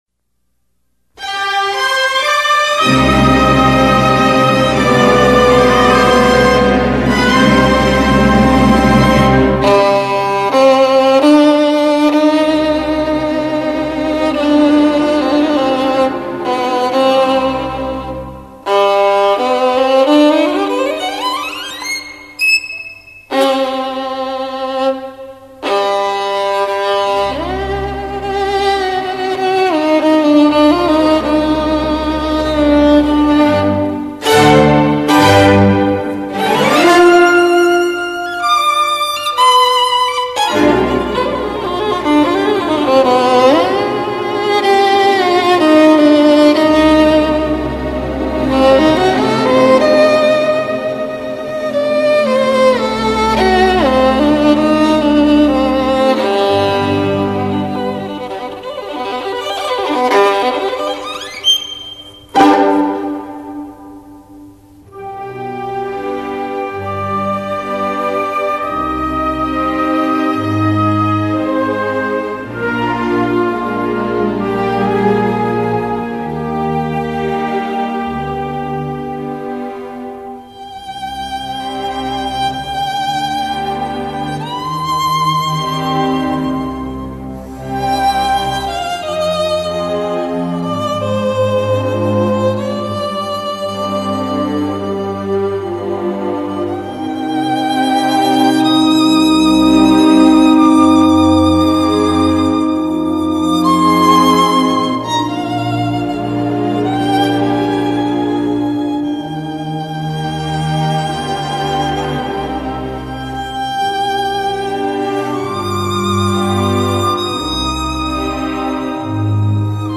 这一首乐曲是萨拉萨蒂所有作品中最为世人所熟悉的名作，它那回肠荡气的伤感色彩与艰涩深奥的小提琴技巧所交织出来的绚烂效果，任何人听后都会心荡神驰不已。
本曲中的管弦乐伴奏部分，也是作者亲自编配的。
第一部分：中板，C小调，4/4拍子。由强而有力的管弦乐齐奏作为开始，然后主奏小提琴奏出充满忧伤的旋律。这一部分很短，只是全曲的引子。
第二部分：缓板，由小提琴奏出新的旋律，是一种美丽的忧郁，以变奏和反复做技巧性极强的发展，轻巧的泛音和华丽的左手拨弦显示出这一主题的丰富内涵。在这部分，管弦乐并不太明显，始终是以小提琴的轻柔旋律为主题。
第三部分：稍为缓慢的缓板，2/4拍子。小提琴装上弱音器，"极有表情地"奏出充满感伤情调的旋律，悲伤的情绪达到极点。这一旋律广为人知。
第四部分：2/4拍子，急变为极快的快板， 有与第二、三部分形成明显对比的豪迈性，反映出吉普赛民族性格的另一面--能歌善舞。以管弦乐的强奏作为先导，小提琴演奏出十分欢快的旋律，右手的快速拨奏与高音区的滑奏无比欢愉;这一旋律告一段落后，又用小提琴的拨奏开始新的旋律，接着是由十六分音符的断奏所构成的像游丝般的旋律，充满舞蹈气氛；然后以更具技巧性的拨奏再现第四部分的最初部分，逐渐朝气蓬勃地趋于高潮，最后像闪电般结束乐曲。